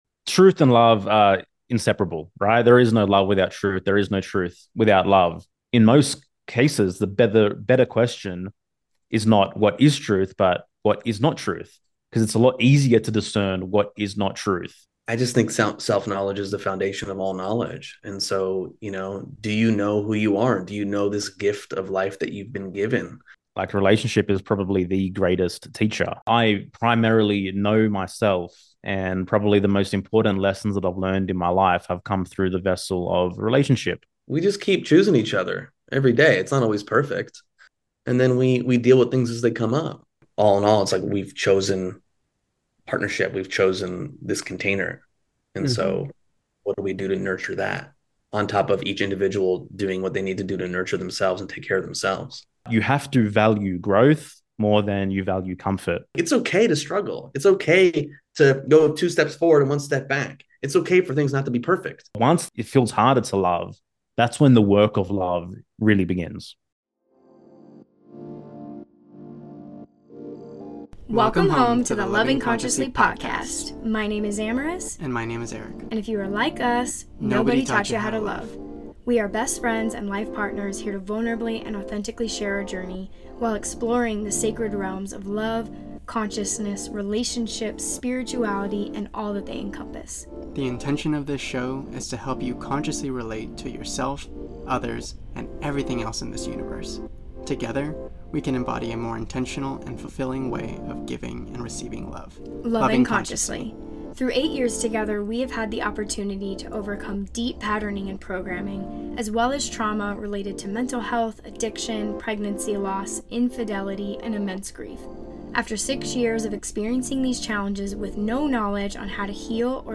a conversation about love, truth, and the power of authenticity 1:03:41